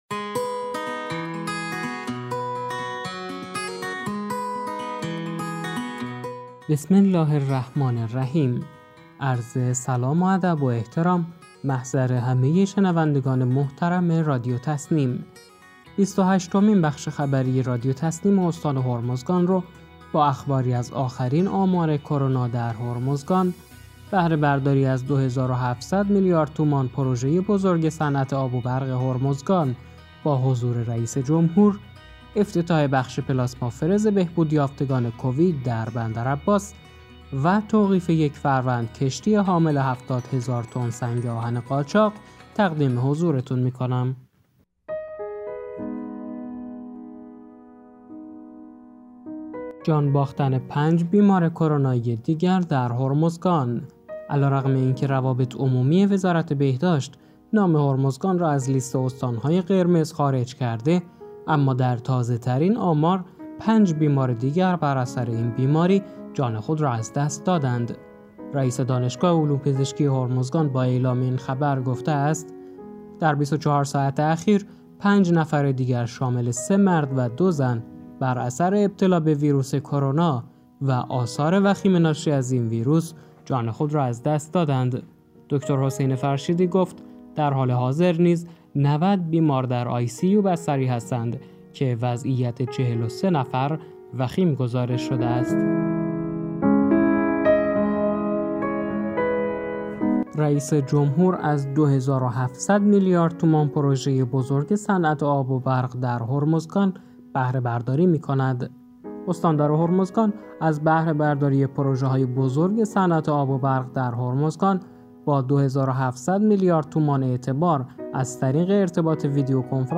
به گزارش خبرگزاری تسنیم از بندرعباس، بیست و هشتمین بخش خبری رادیو تسنیم استان هرمزگان با اخباری از آخرین آمار کرونا در هرمزگان، بهره‌برداری از 2700 میلیارد تومان پروژه بزرگ صنعت آب و برق هرمزگان با حضور رئیس جمهور، افتتاح بخش پلاسما فرز بهبود یافتگان کووید در بندرعباس و توقیف یک فروند کشتی حامل 70 هزار تن سنگ آهن قاچاق در هرمزگان منتشر شد.